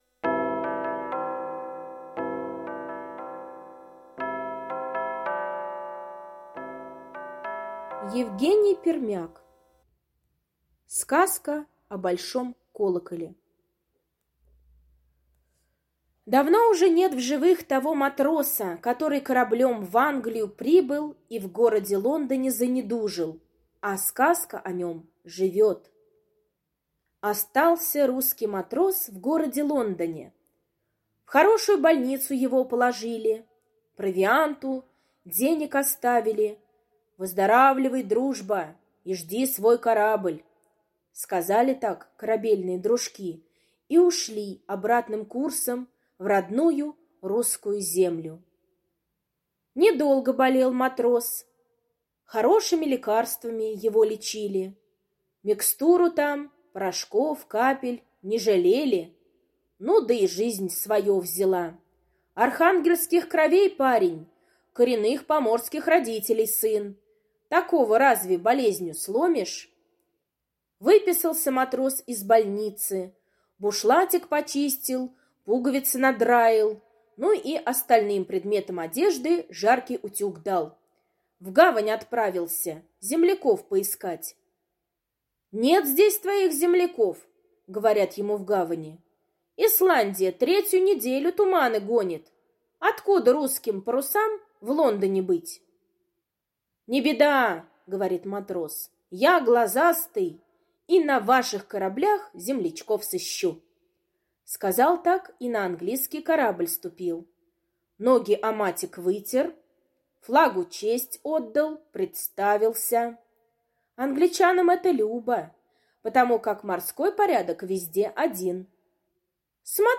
Сказка о большом колоколе — аудиосказка Пермяка Е. казка о русском матросе, который прибыл с кораблем в Англию и там заболел...